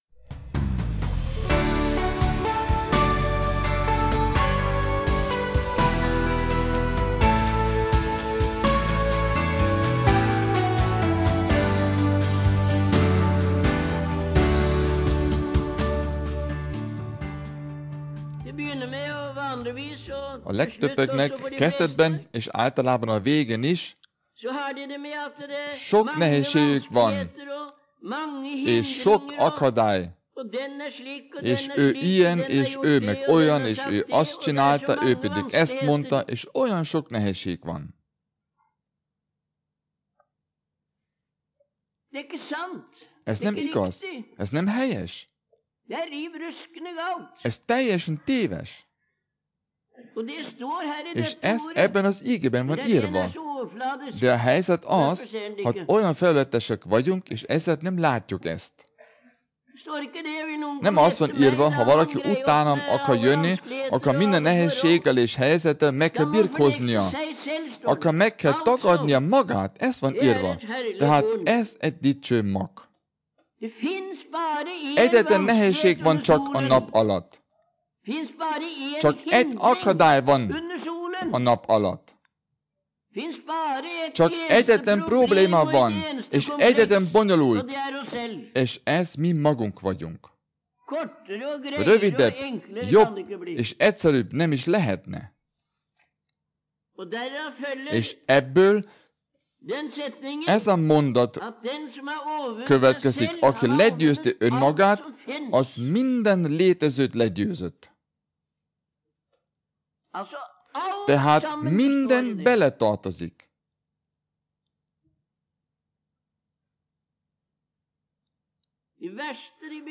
Taleutdrag fra januar 1973